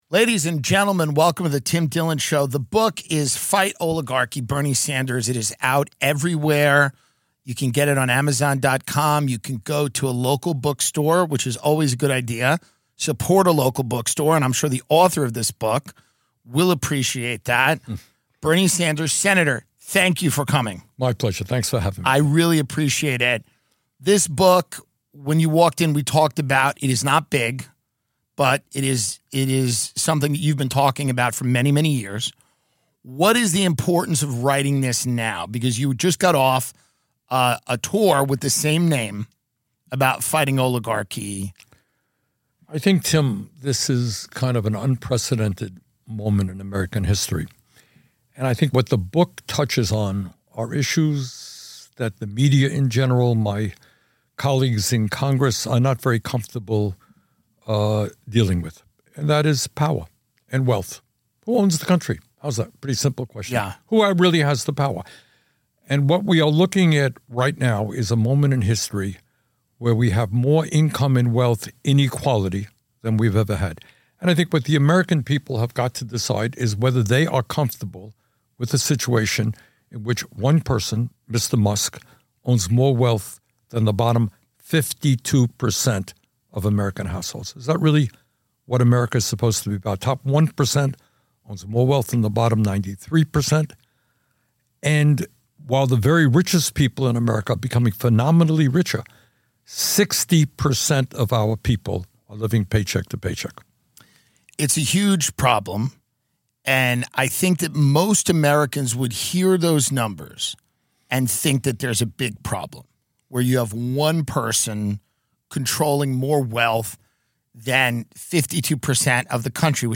Tim Dillon sits down with Senator Bernie Sanders to discuss his new book “Fight Oligarchy”, immigration, American funding of the wars in Ukraine & Gaza, the Democratic Party, transgender issues, and affordability of healthcare.